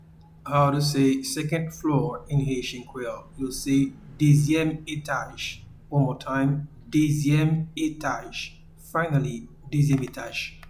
Pronunciation and Transcript:
Second-floor-in-Haitian-Creole-Dezyem-etaj.mp3